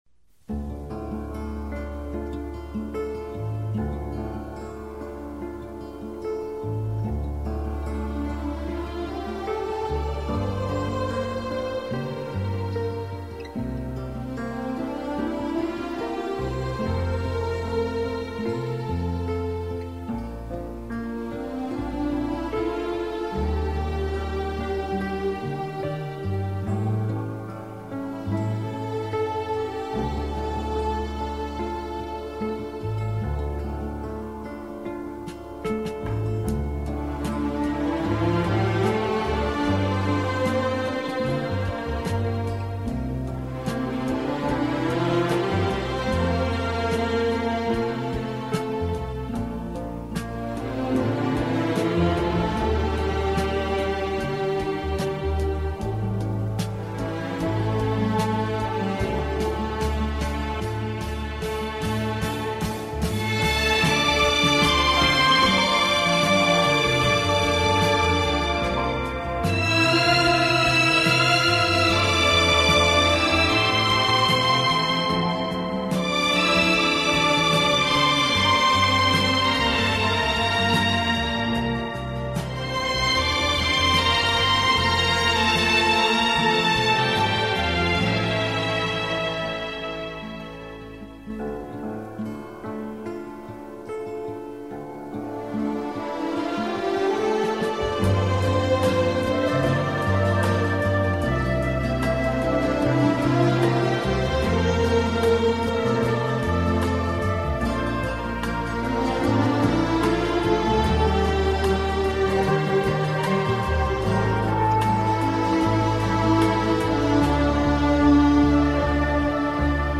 شنونده آهنگ بی کلام و عاشقانه «مرگ برای عشق» باشید